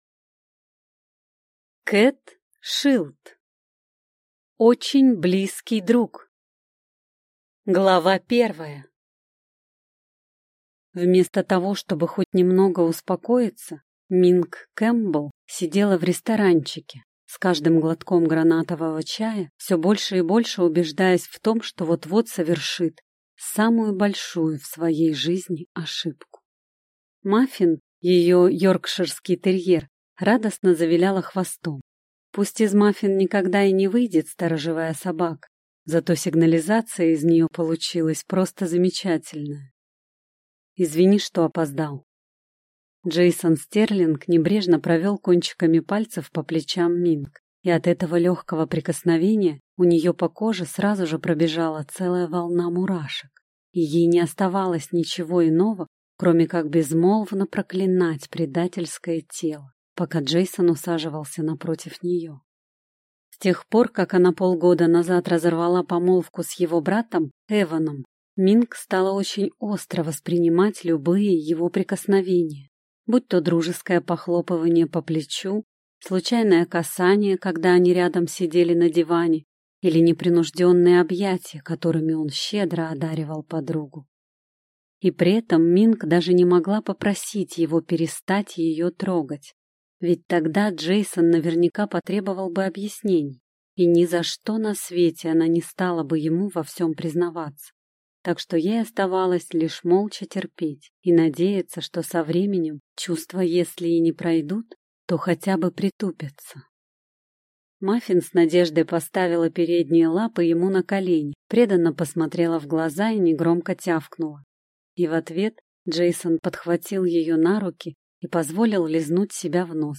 Аудиокнига Очень близкий друг | Библиотека аудиокниг
Прослушать и бесплатно скачать фрагмент аудиокниги